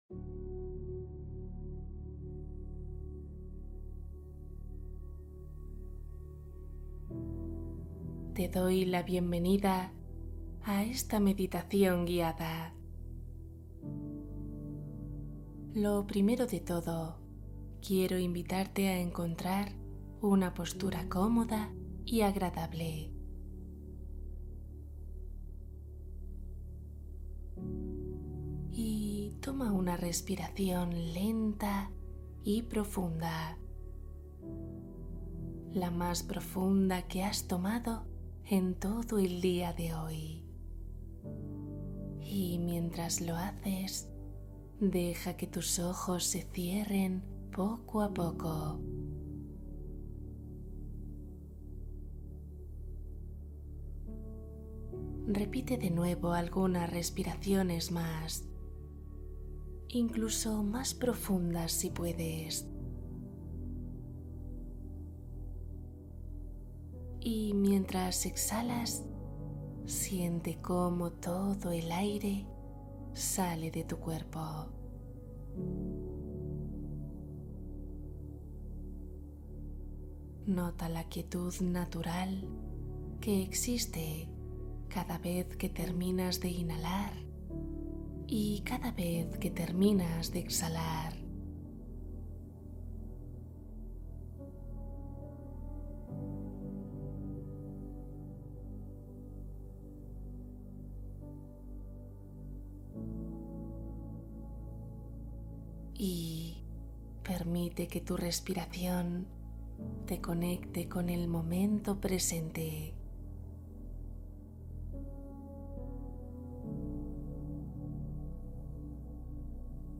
Descanso profundo Cuento y meditación para dormir profundamente